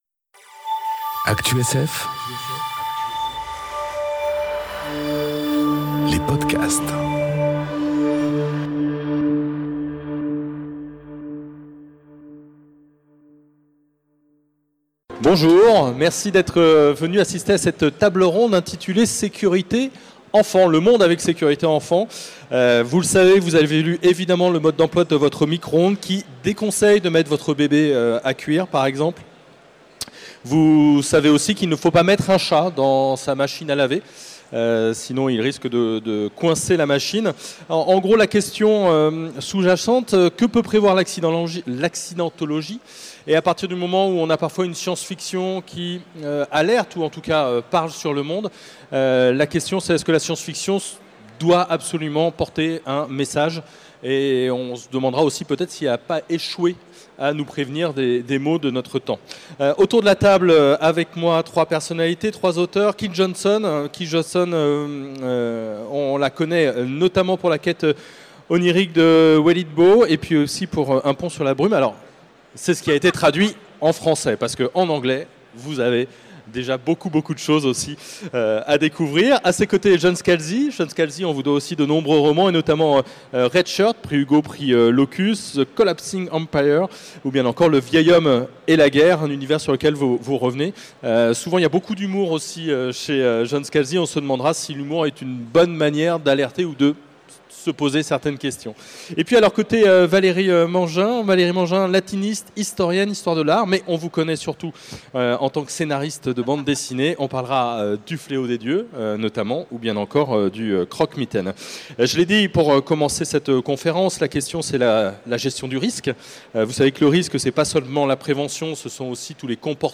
Conférence Le monde avec « Sécurité Enfant » enregistrée aux Utopiales 2018